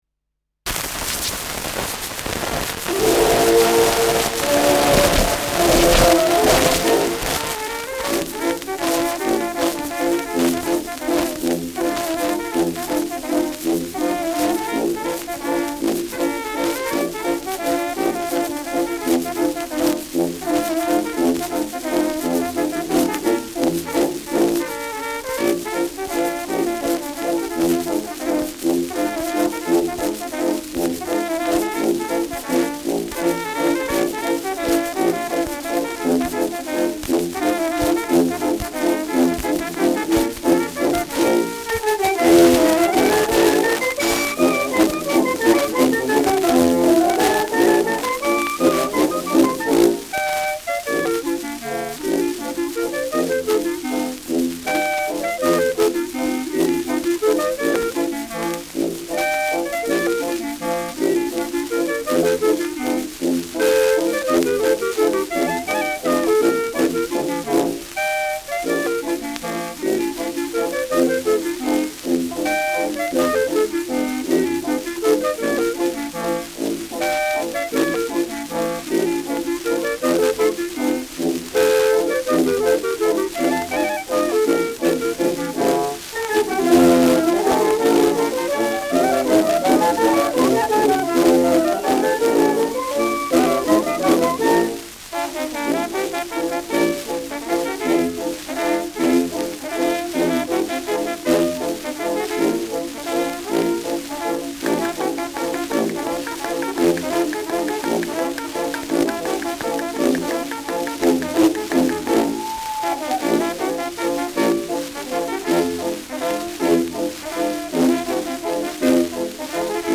Schellackplatte
zu Beginn starkes Rauschen : leichtes Rauschen : präsentes Nadelgeräusch : leichtes Knistern : abgespielt : leichtes Leiern : gelegentliches „Schnarren“
[München] (Aufnahmeort)